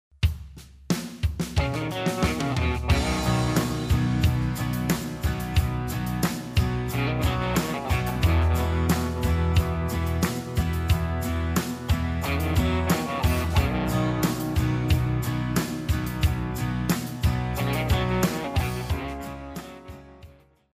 Country Music Samples
Country 103